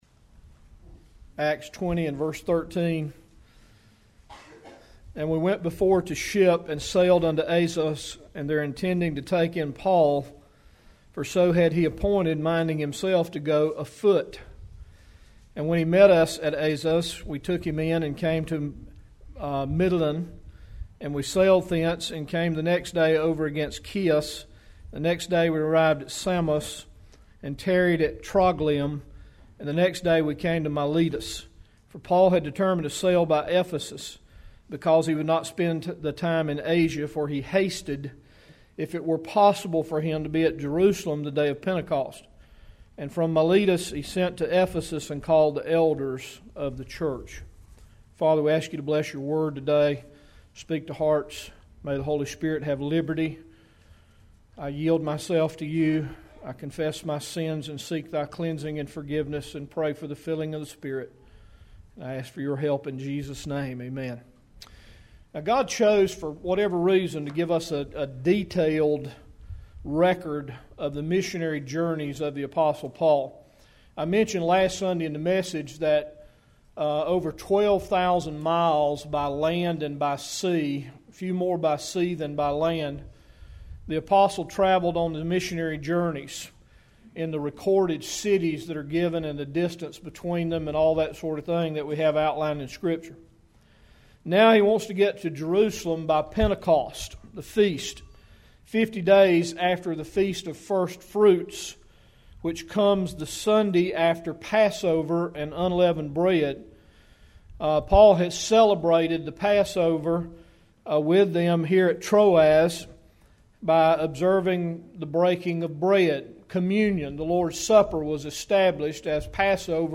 May 11, 2014 – AM- Mother’s Day – Bible Baptist Church